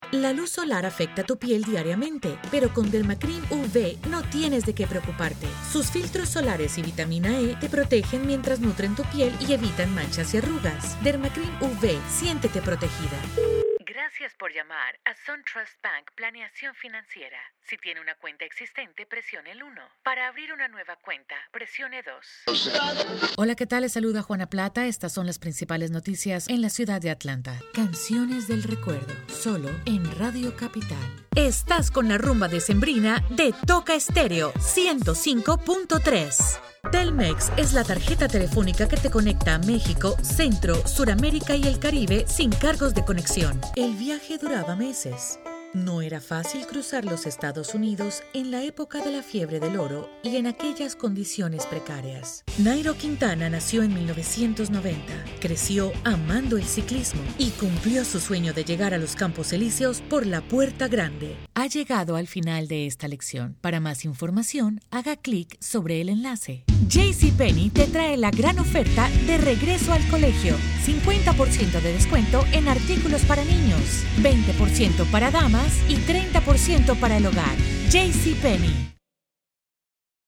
Spanish, Female, Voice Over, Bilingual english/Spanish, neutral accent, elegant. colorful, narration, commercials, e-learning, Corporate, training
Sprechprobe: Werbung (Muttersprache):
Spanish/English (light latino accent) with 20+ years in the industry.